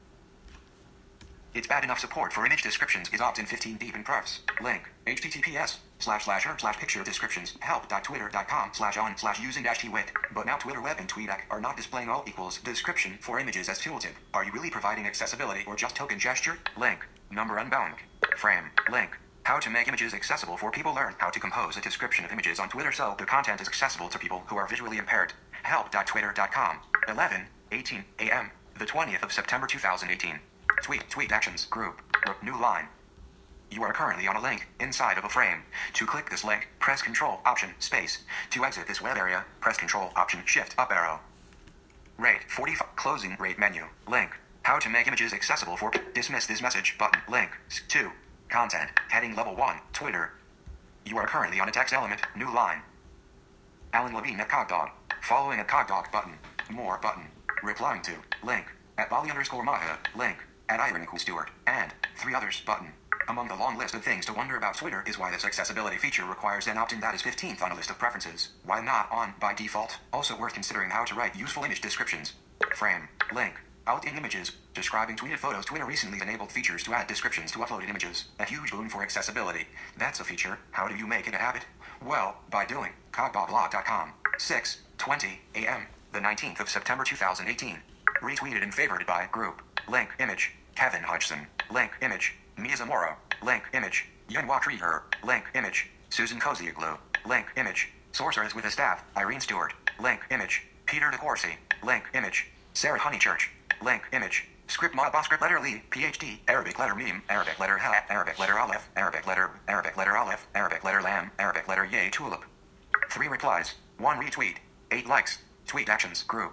First listen to the recording of tweets being read:
more-tweets-read.mp3